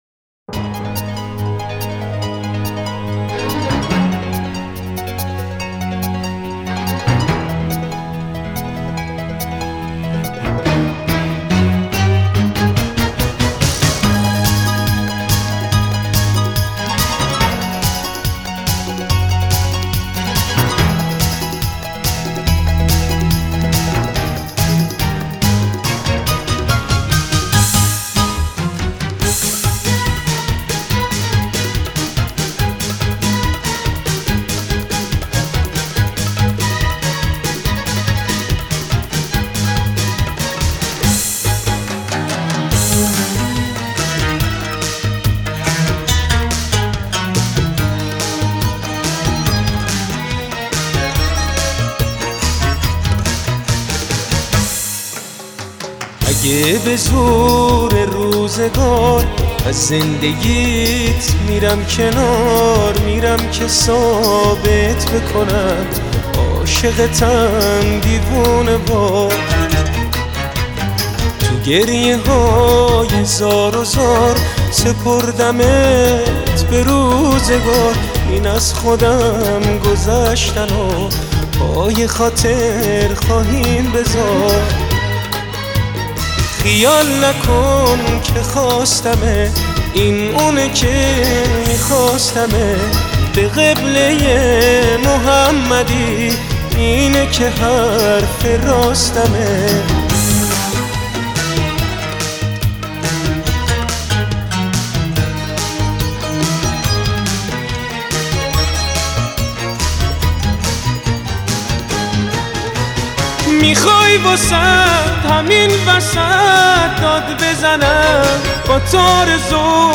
آهنگ نوستالژیک آهنگ احساسی